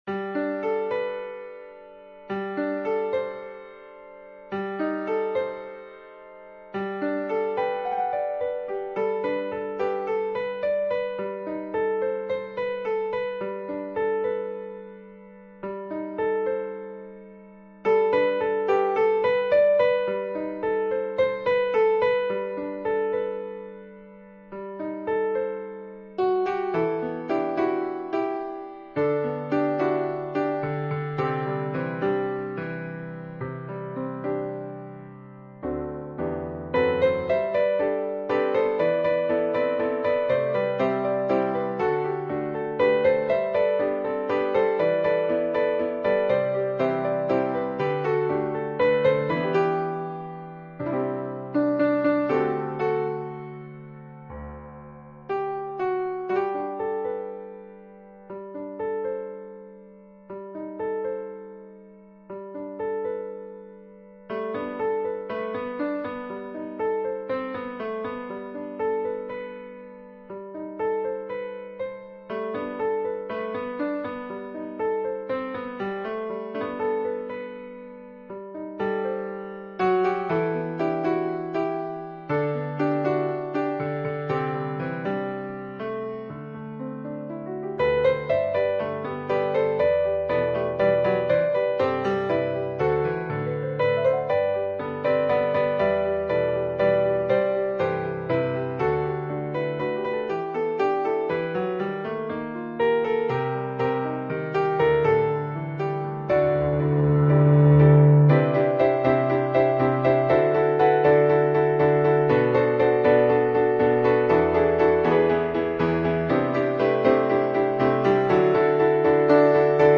「合唱」種類數位樂譜，最低訂購數量為20份，樂譜單價將以團體價80元/份計。